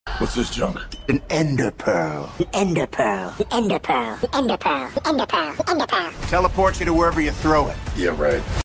ender-pearl-meme-sound